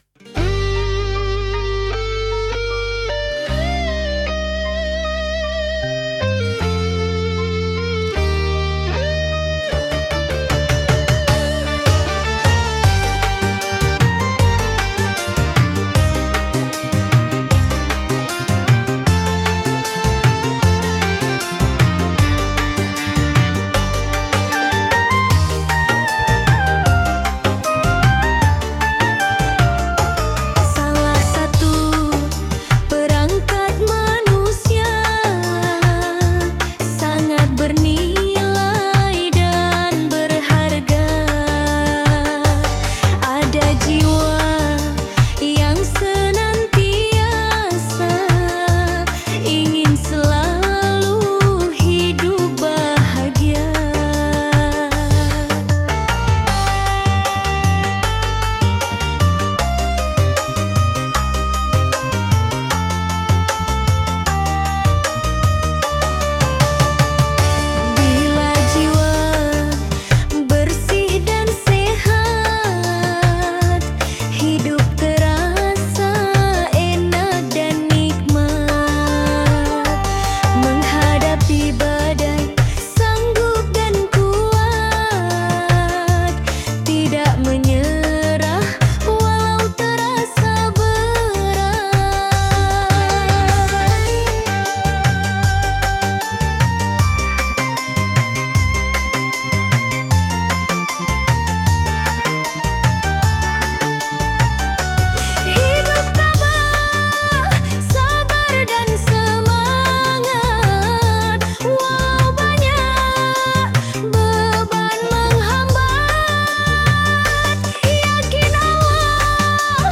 Dengarkan lantunan lagu dan musik yang syahdu syair tersebut